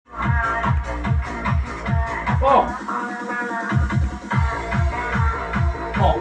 Nuta, która leciała u mechanika xD - Muzyka elektroniczna